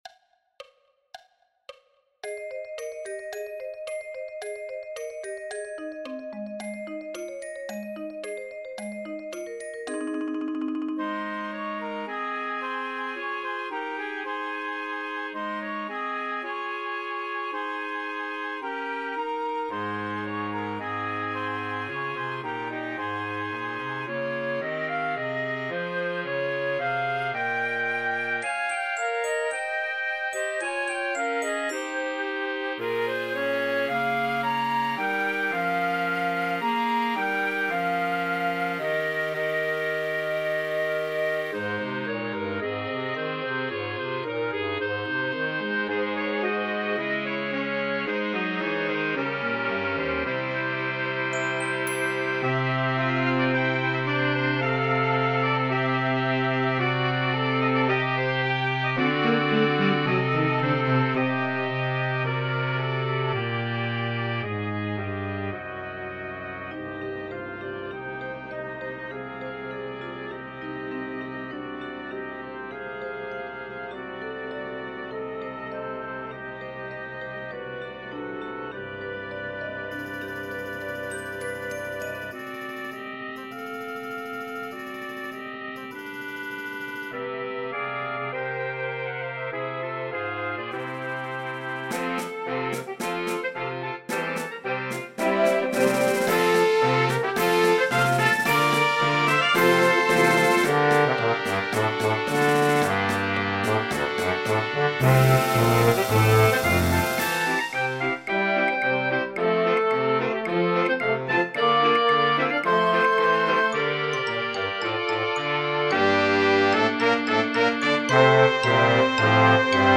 The percussion starts bringing an enchanting atmosphere.